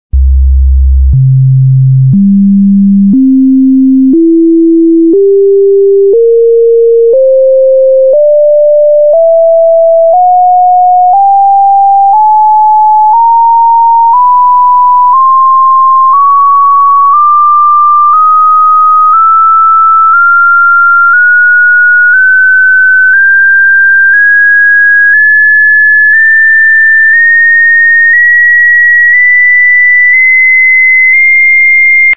(1. bis 32. Partialton) erfahrbar!
Die ersten 32 Partialtonfrequenzen des Tons C (66 Hz)